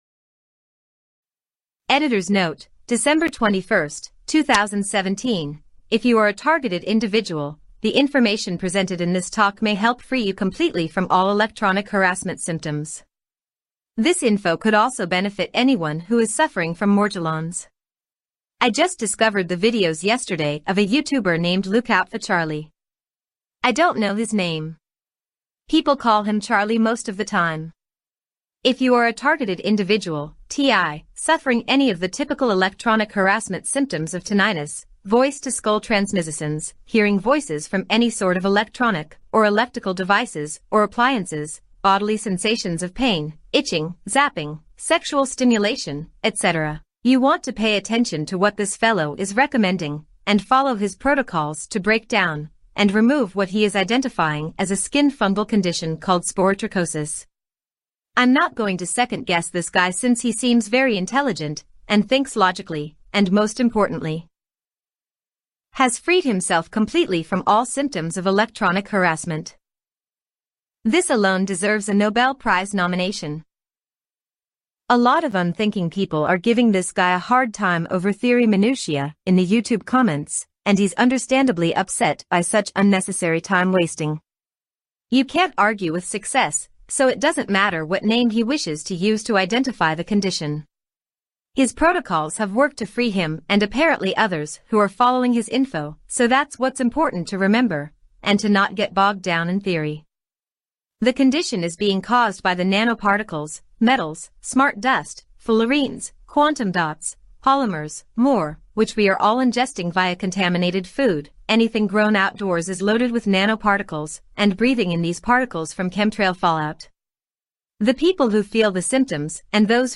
Targeted-Individuals-Audio-Reading-Parts-1-7.mp3